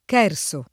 [ k $ r S o ]